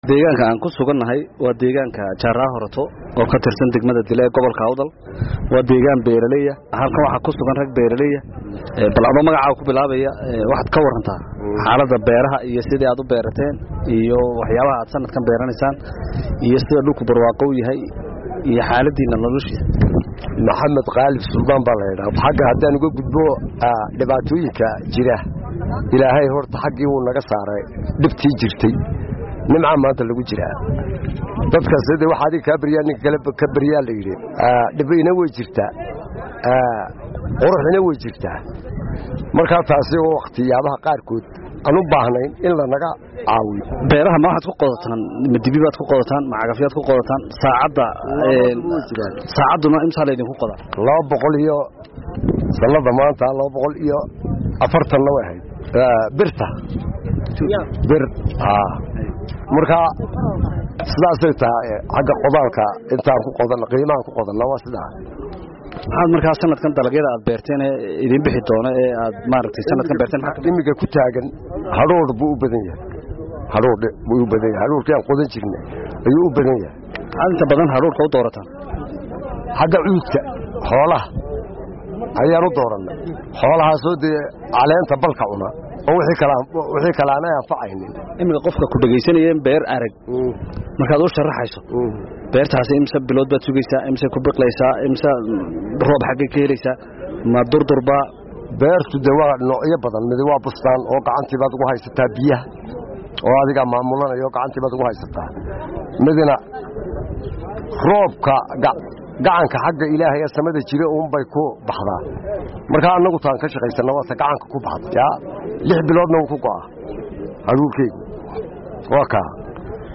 Warbixin: Beerashada Gobolka Awdal